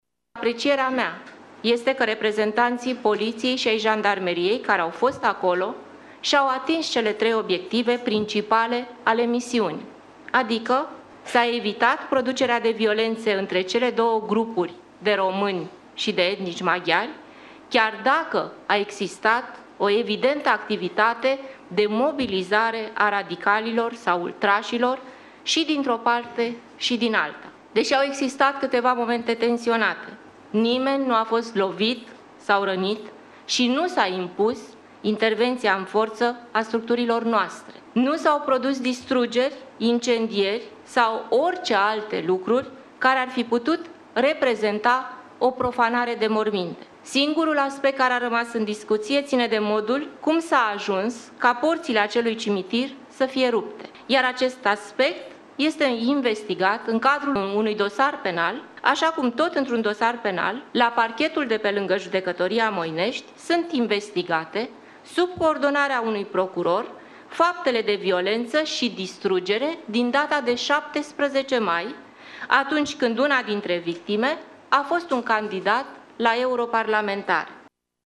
In prezent, în acest caz este în derulare o anchetă, a subliniat șefa MAI: